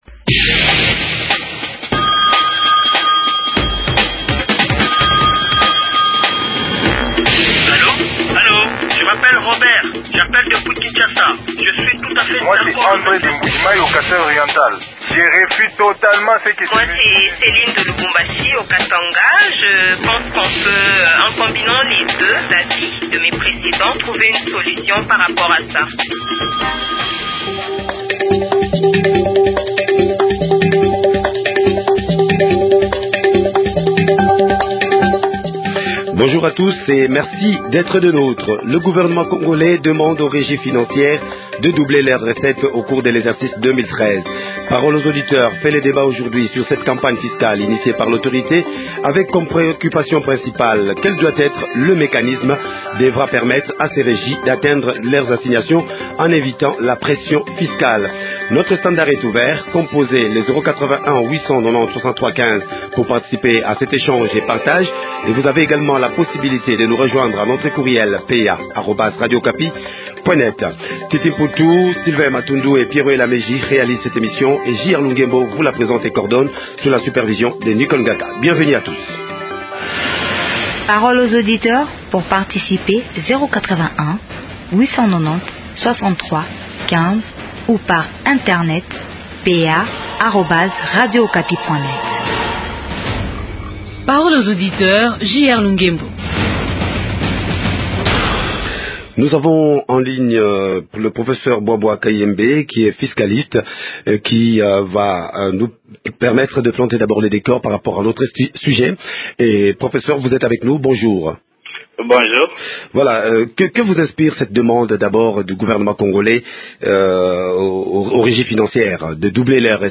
Parole aux auditeurs vous propose ce mercredi 6 février de débattre sur le rendement des ces régies financières.